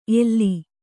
♪ elli